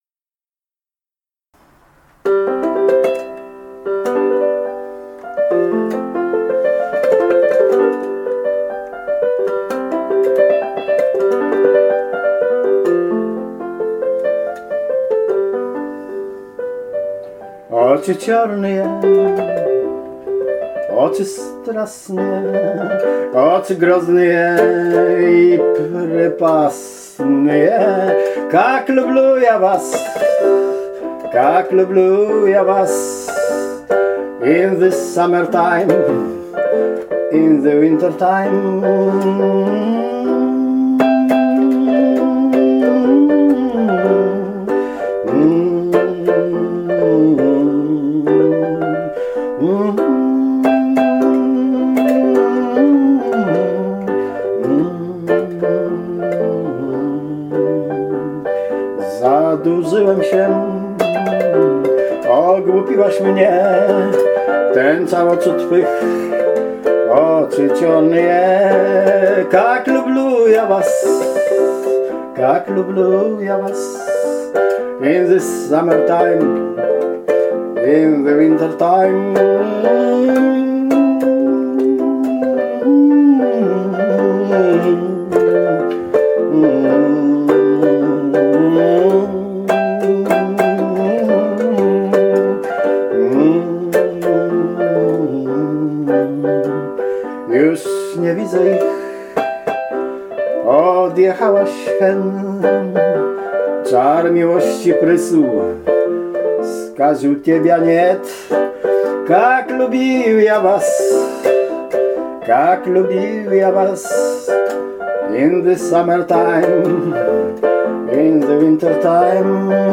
Rosyjskie romanse w nietypowym wykonaniu